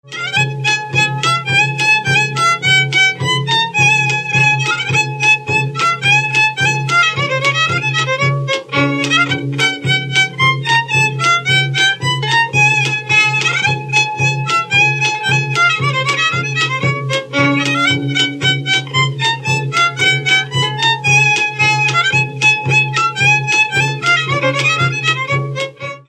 Dallampélda: Hangszeres felvétel
Erdély - Csík vm. - Csíkszentdomokos
hegedű
ütőgardon
Műfaj: Féloláhos
Stílus: 7. Régies kisambitusú dallamok
Kadencia: 5 (1) 5 1